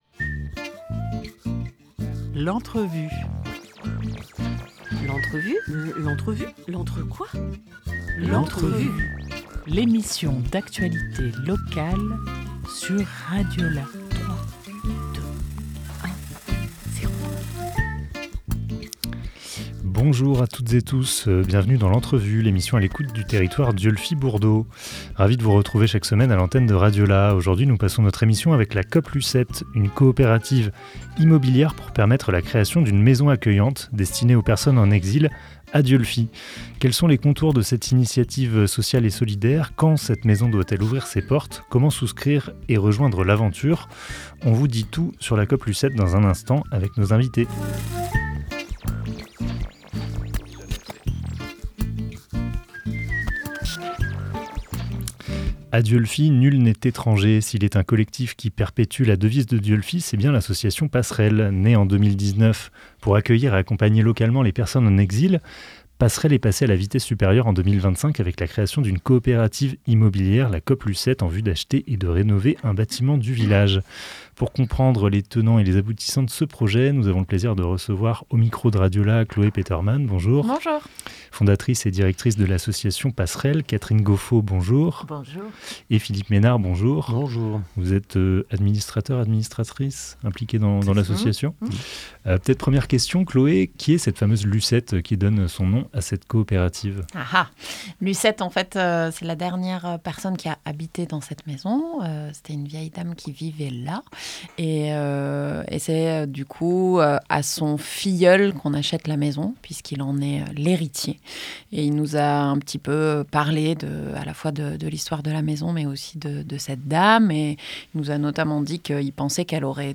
15 avril 2025 11:12 | Interview